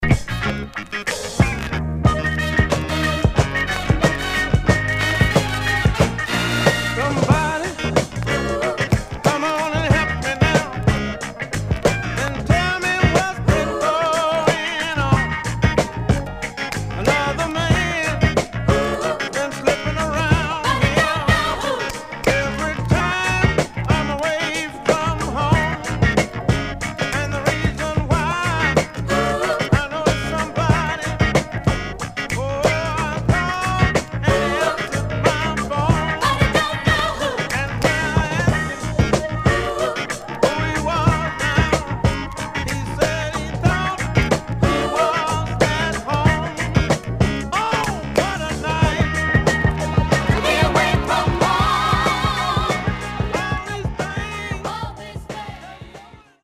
Mono
Funk